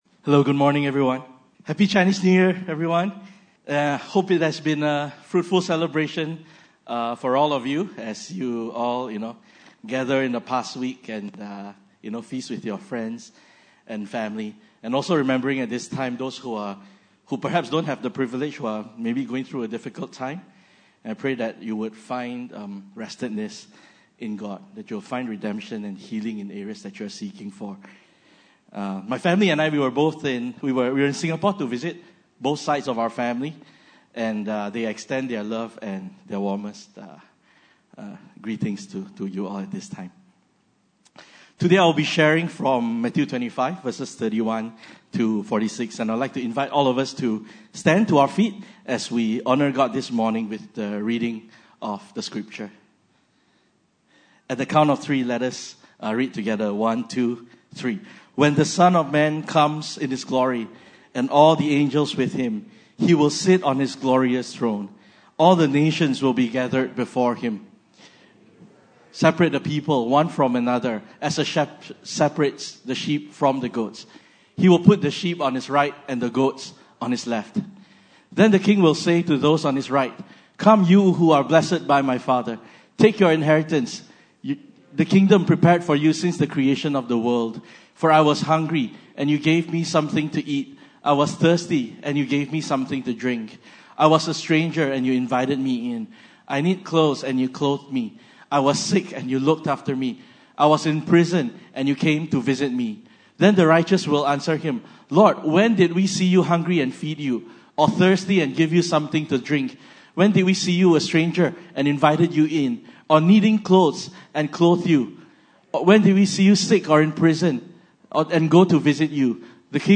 Passage: Matthew 25:31-46 Service Type: Sunday Service